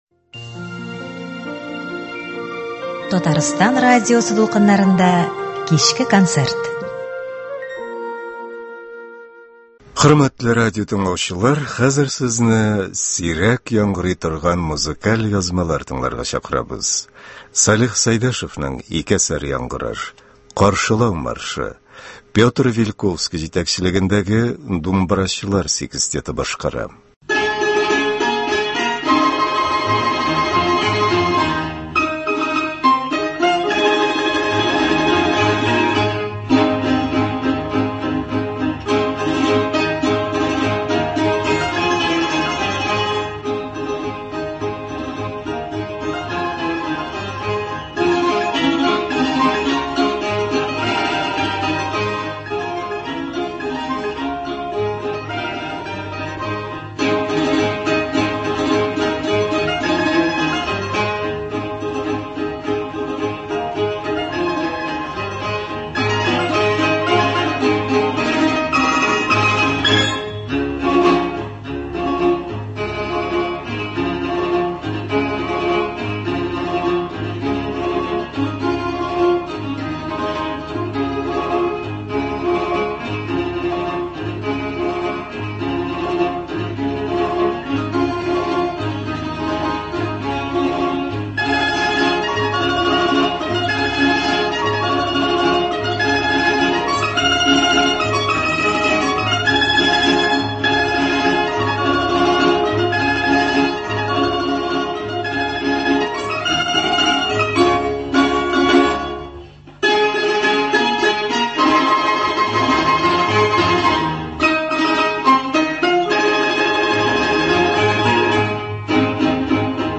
Сирәк яңгырый торган музыкаль язмалар